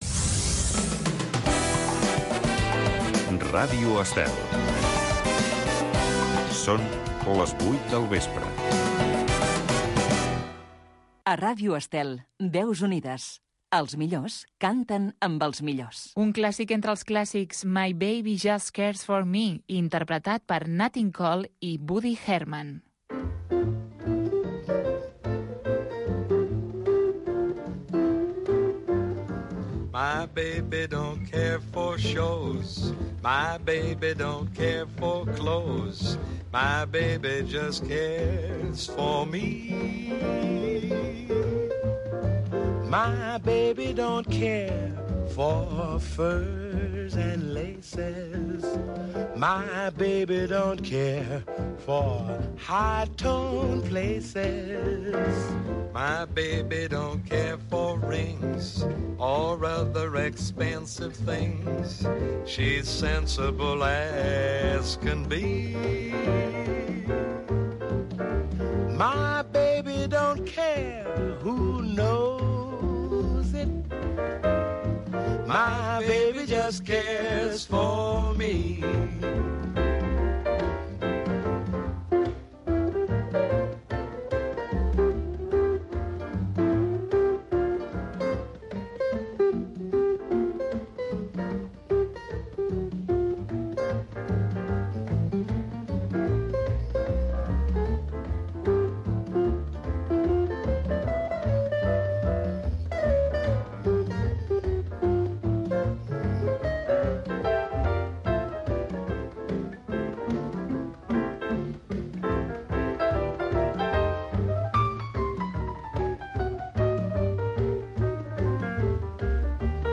Momenti della canzone italiana. Programa que emet les millors cançons lleugeres italianes des del segle XX a l'actualitat.